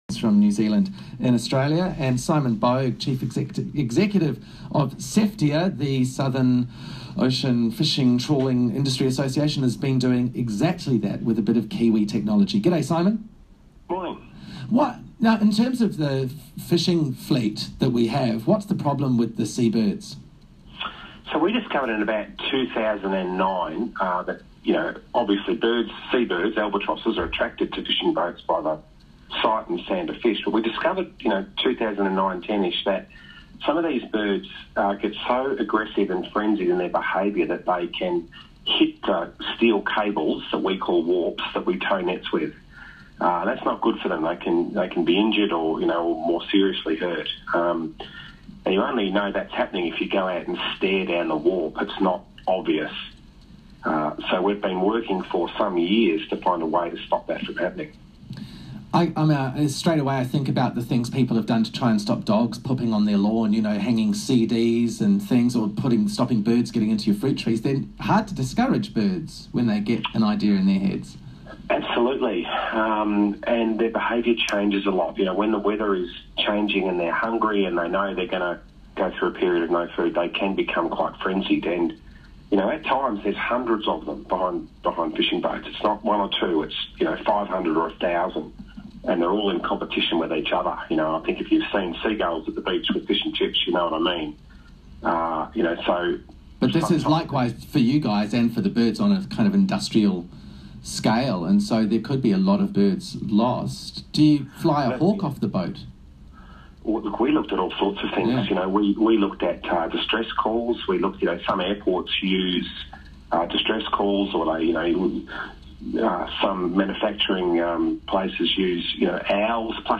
SETFIA Interview with ABC – Seabird Bafflers